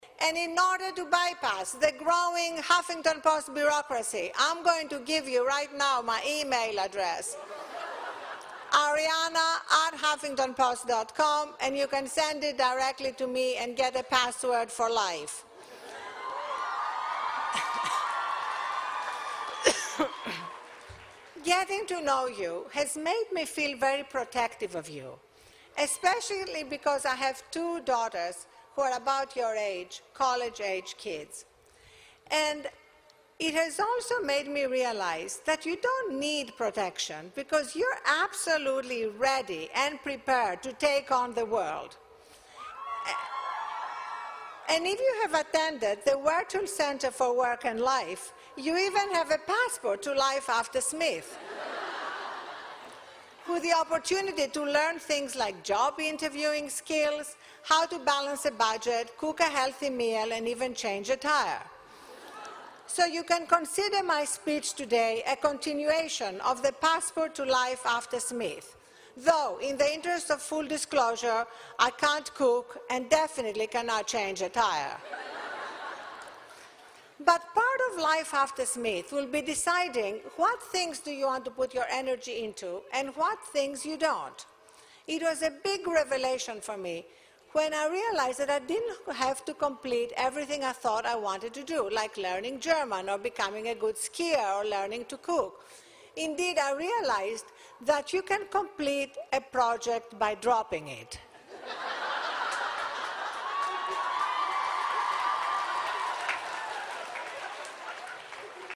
公众人物毕业演讲 第313期:阿丽安娜.哈芬顿2013史密斯学院(3) 听力文件下载—在线英语听力室